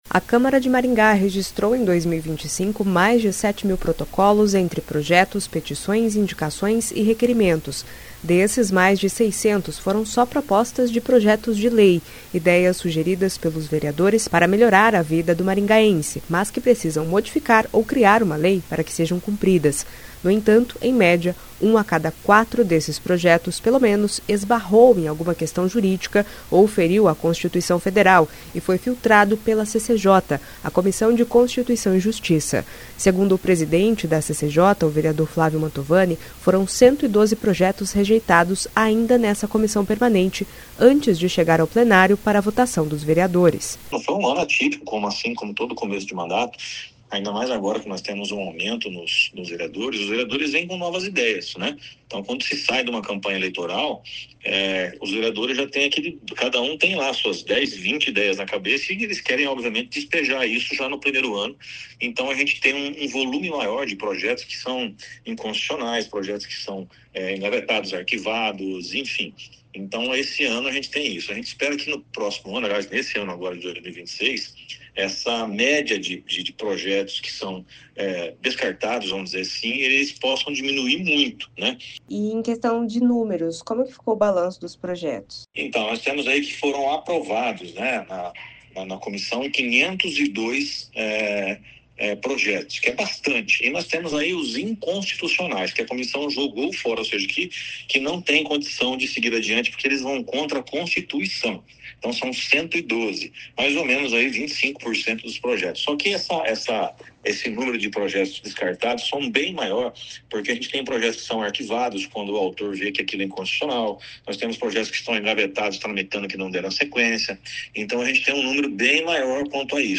Segundo o presidente da CCJ, vereador Flávio Mantovani, foram 112 projetos rejeitados ainda nessa comissão permanente, antes de chegar ao plenário para votação dos vereadores. [ouça o áudio acima]